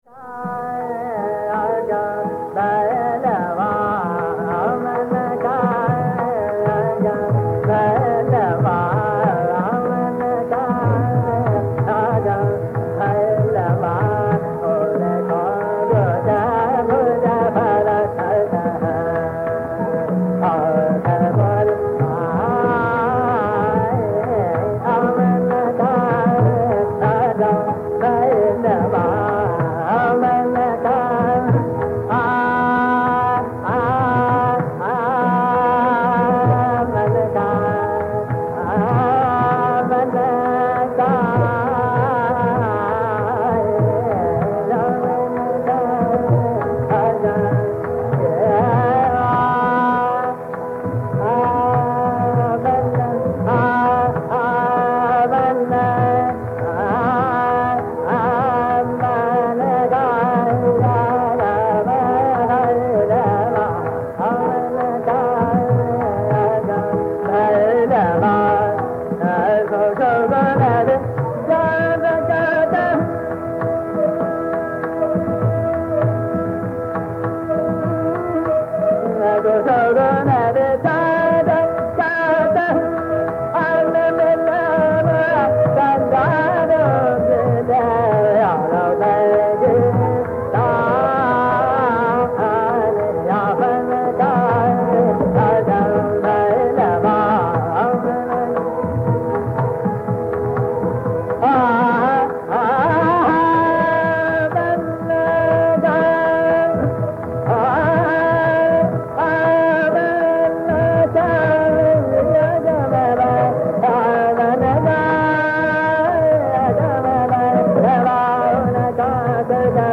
Raga Poorvi
Azmat Hussain Khan who had his feet in both the Atrauli and Agra schools.